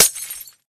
glass1.ogg